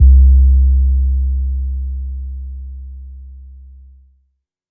808 (Couch).wav